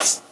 Shake_2.wav